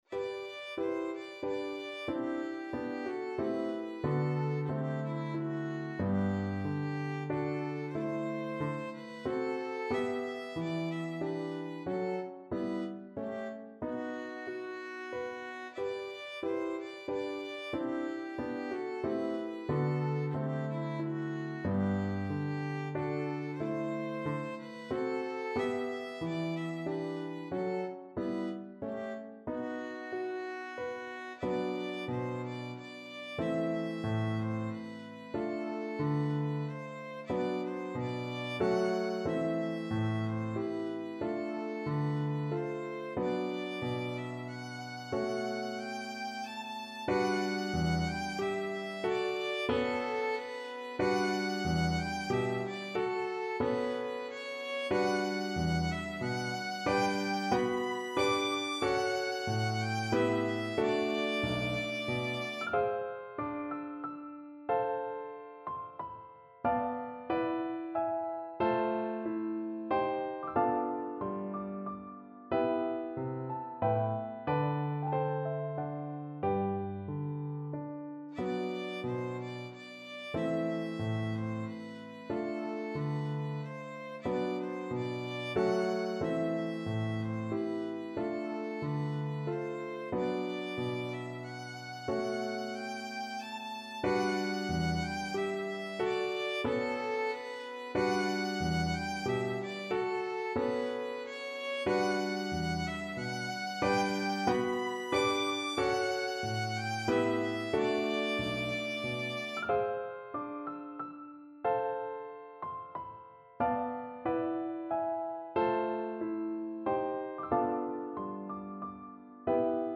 3/4 (View more 3/4 Music)
Allegro moderato =92 (View more music marked Allegro)
Classical (View more Classical Violin Music)